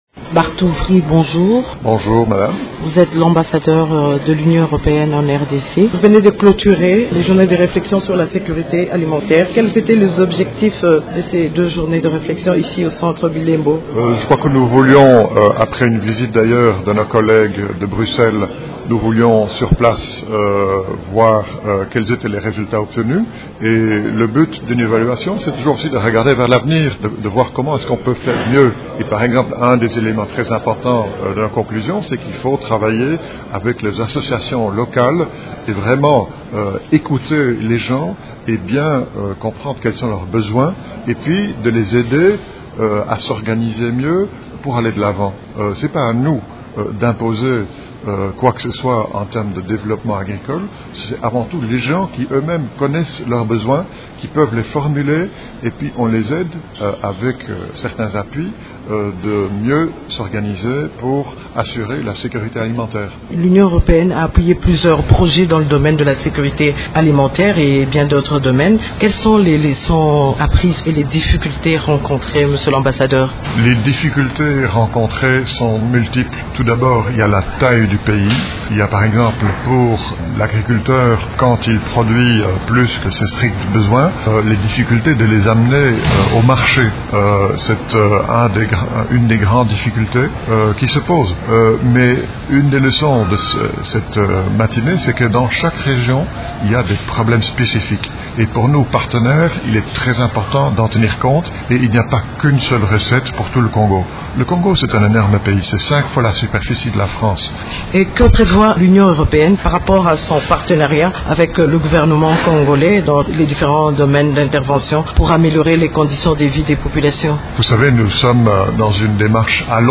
invite_ambassadeur_ue_barth_ouvry.mp3